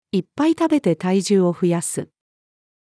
いっぱい食べて体重を増やす。-female.mp3